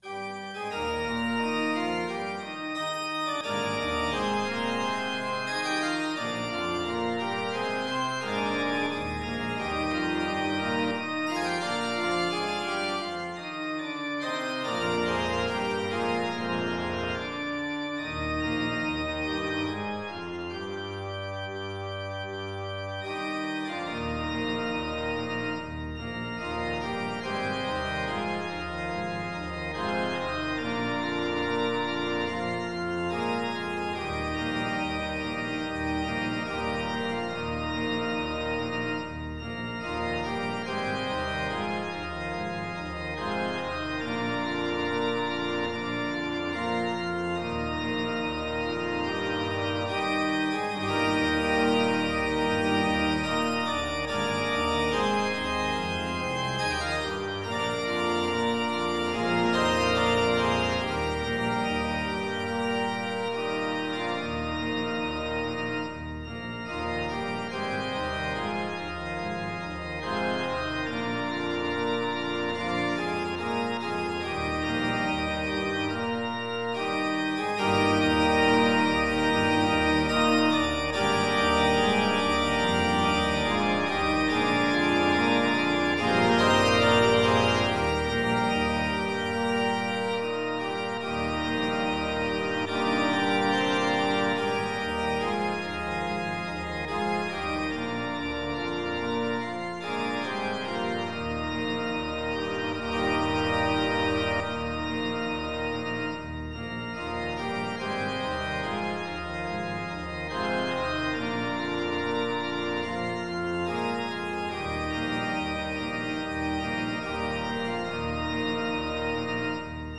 Voicing/Instrumentation: SATB We also have other 3 arrangements of " Let Us Oft Speak Kind Words ".